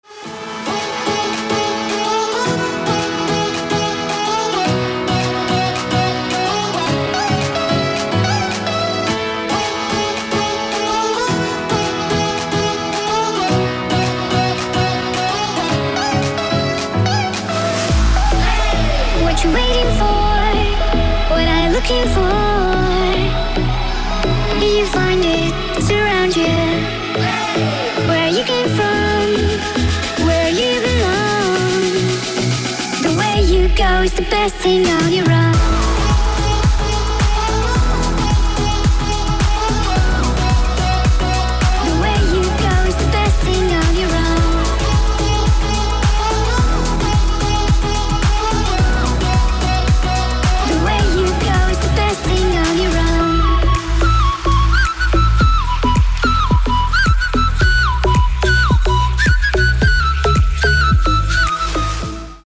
мелодичные
dance
Electronic
спокойные
пианино
Флейта
vocal
Стиль: Tropical House